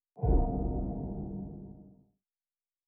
令牌道具获取.wav